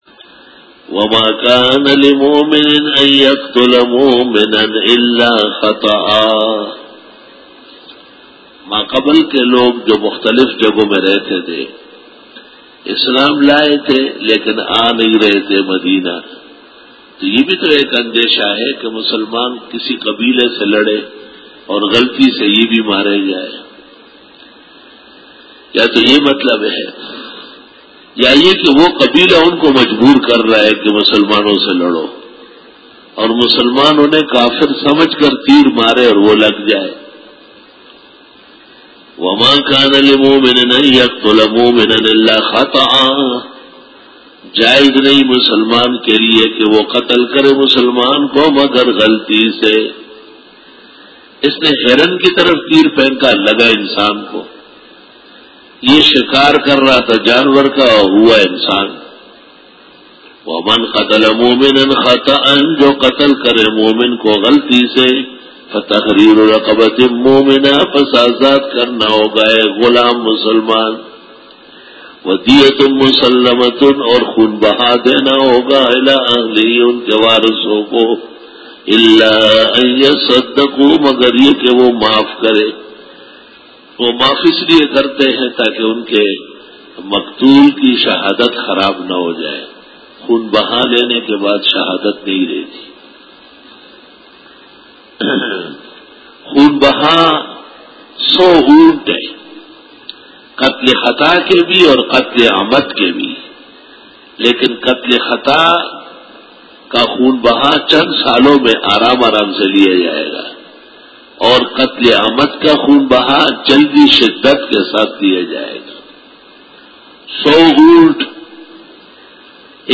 Dora-e-Tafseer